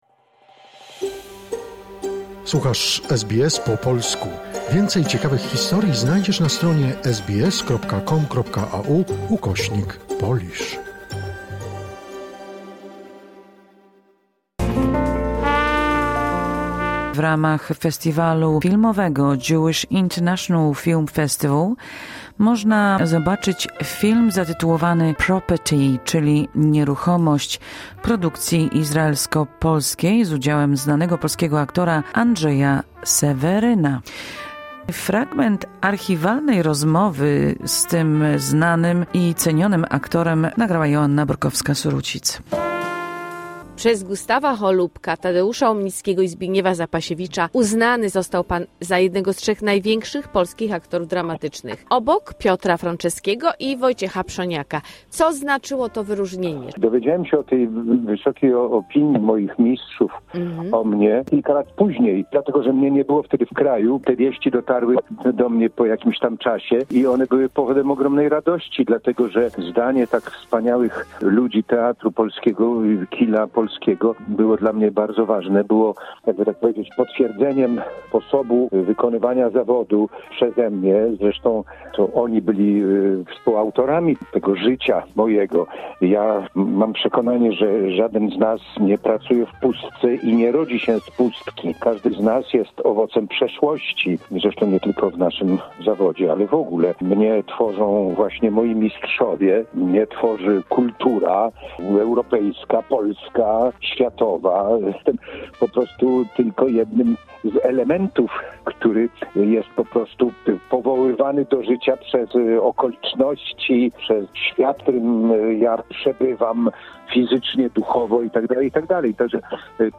Na festiwalu filmów Jewish International Film Festival pokazany będzie film pt. "Property" produkcji izraelsko-polskiej z udziałem znanego polskiego aktora Andrzeja Seweryna. Oto fragment rozmowy o twórczości i karierze tego cenionego aktora.